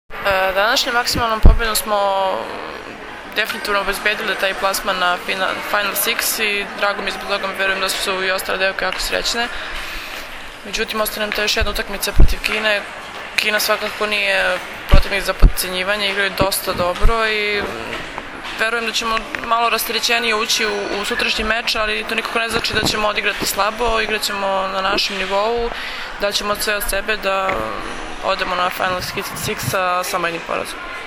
IZJAVA MILENE RAŠIĆ, BLOKERA SRBIJE